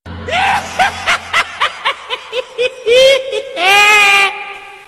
Chucky Laugh sound effects free download